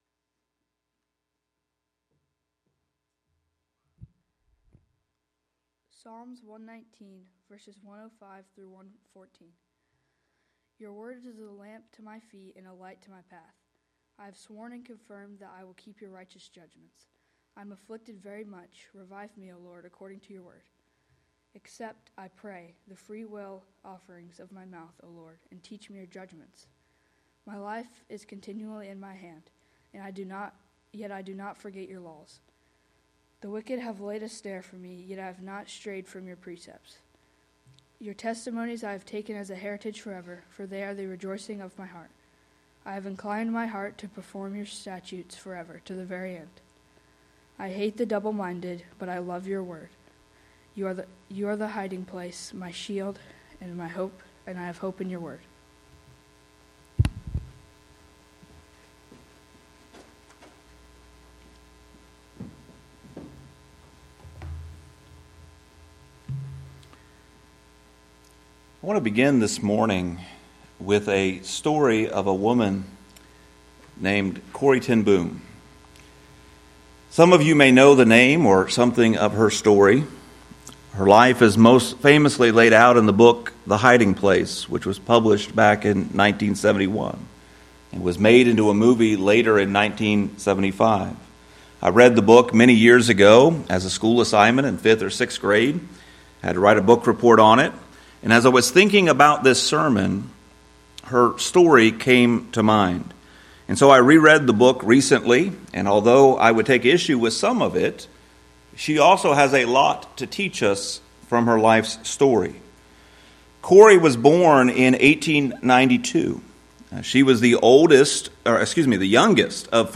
The goal of the sermon is to teach that strong faith is built through small, consistent spiritual habits.